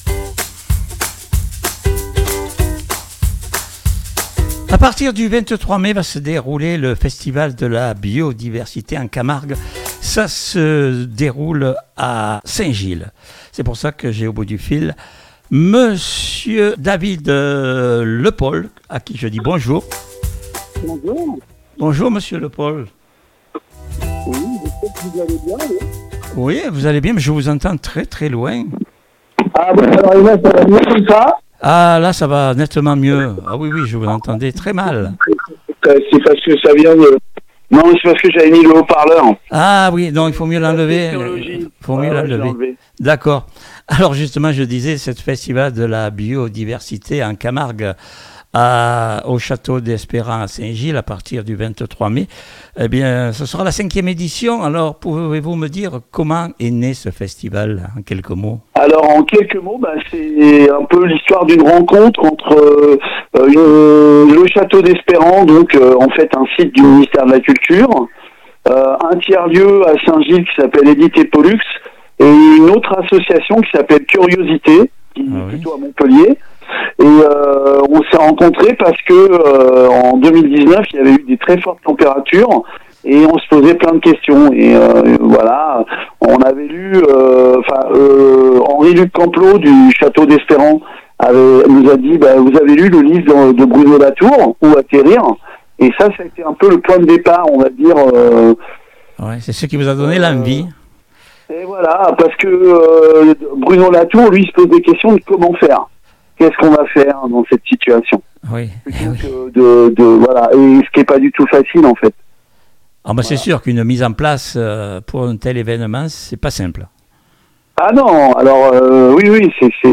FESTIVAL ACTE - INTERVIEW